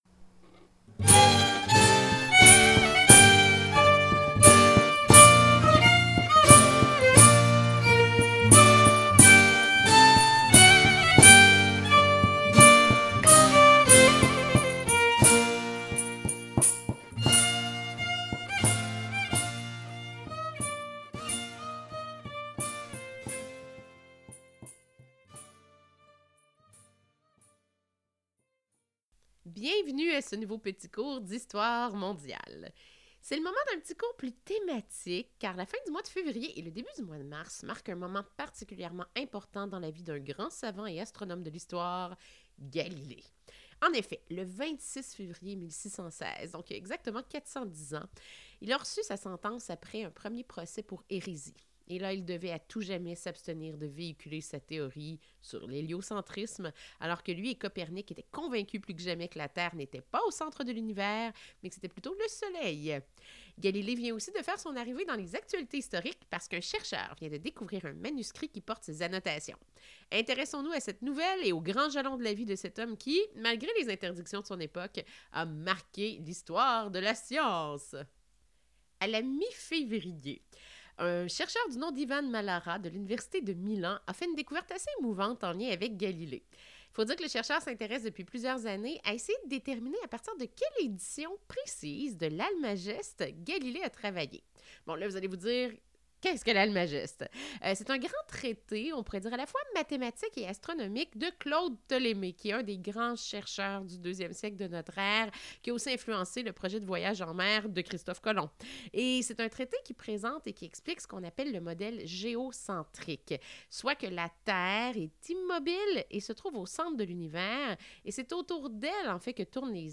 Petit cours thématique qui s\'intéresse à la découverte d\'un manuscrit qui contient des annotations possibles de Galilée et par la suite à l\'histoire de ce grand savant et de ses procès liés à ses recherches sur l\'héliocentrisme.